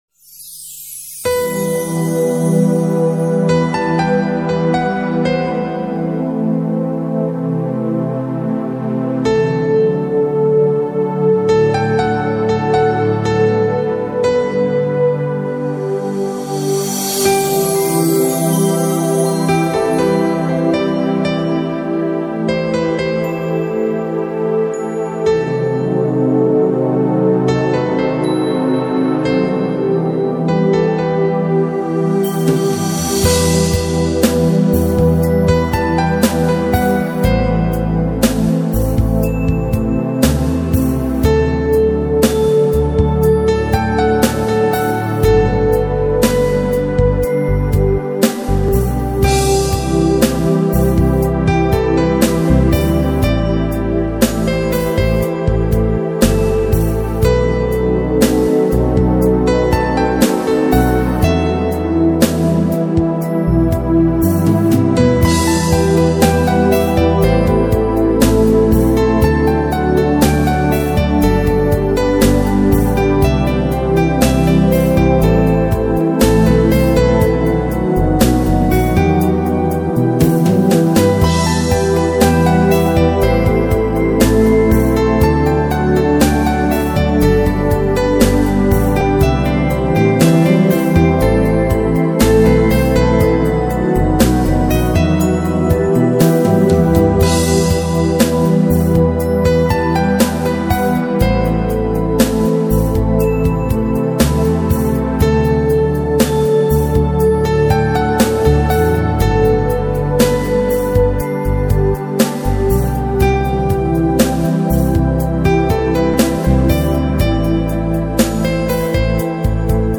красивая_и_нежная_мелодия_
krasivaya_i_nezhnaya_melodiya_.mp3